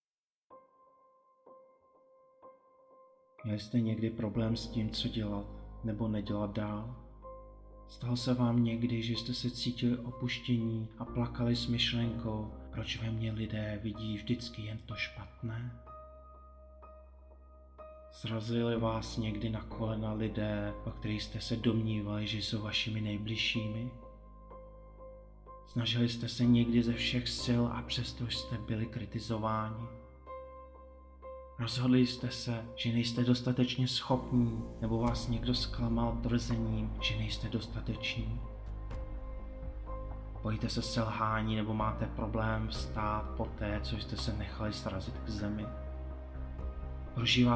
AudioKniha ke stažení, 1 x mp3, délka 8 min., velikost 7,2 MB, česky